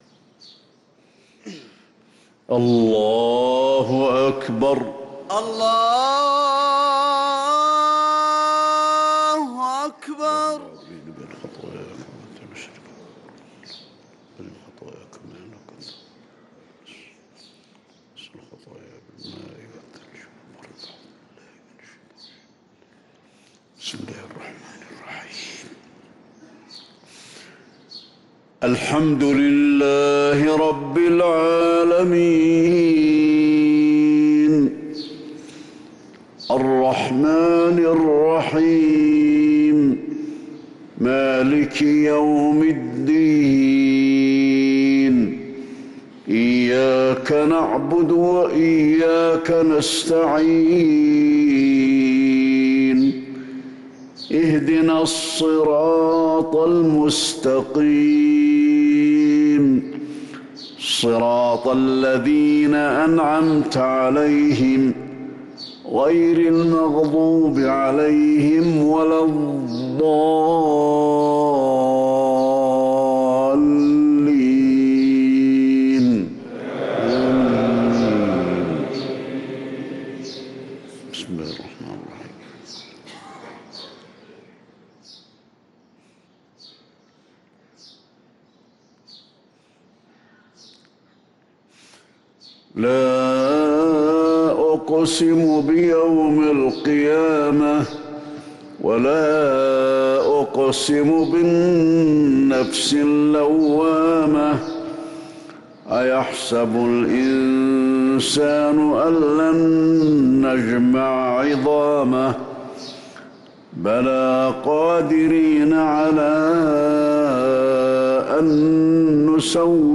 صلاة الفجر للقارئ علي الحذيفي 26 ربيع الأول 1445 هـ
تِلَاوَات الْحَرَمَيْن .